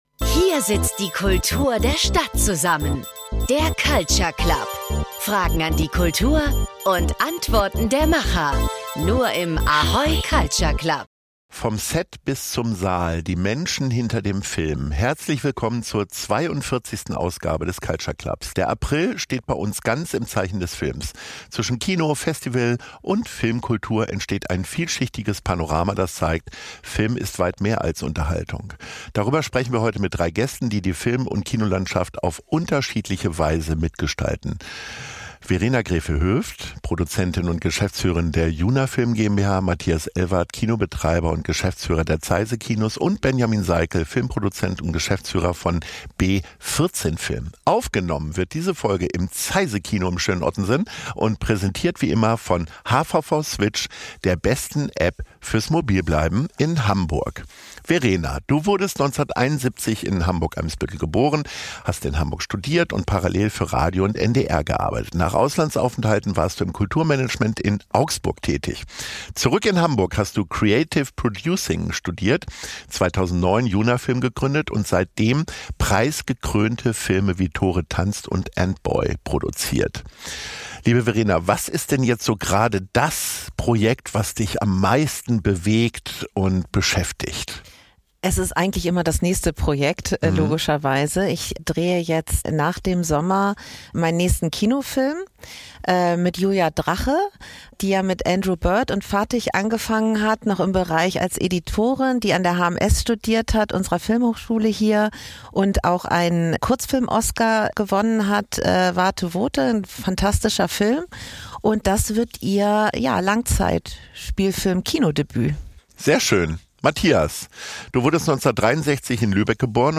Sie sprechen über kreative Prozesse, unternehmerische Entscheidungen und die Frage, wie Filme sichtbar werden. Gleichzeitig geht es um Hamburg als Filmstandort: zwischen Anspruch, Realität und Potenzial. Aufgenommen wurde diese Folge im Zeise Kino in Ottensen und präsentiert wie immer von HVV Switch, der besten App fürs Mobilbleiben in Hamburg.